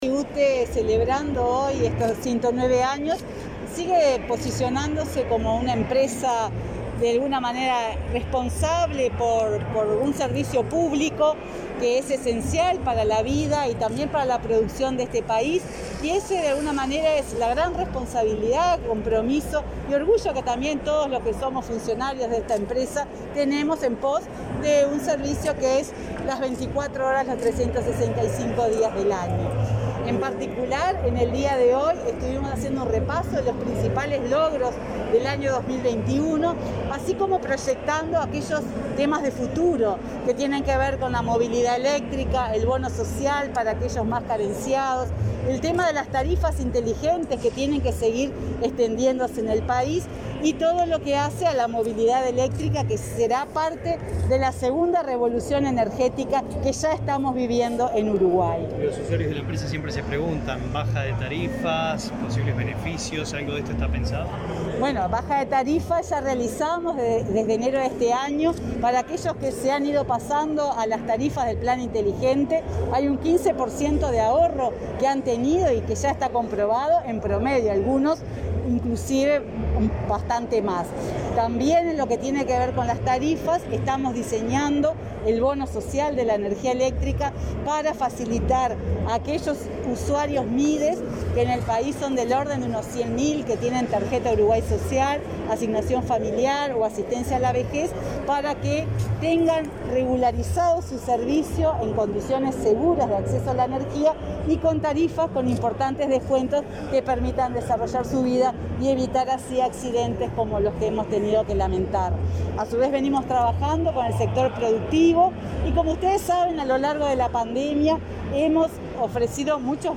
Declaraciones a la prensa de la presidenta de UTE, Silvia Emaldi
Declaraciones a la prensa de la presidenta de UTE, Silvia Emaldi 21/10/2021 Compartir Facebook X Copiar enlace WhatsApp LinkedIn Tras el acto por el aniversario n.° 109 de la Administración Nacional de Usinas y Trasmisiones Eléctricas (UTE), Emaldi efectuó declaraciones a la prensa.